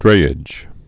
(drāĭj)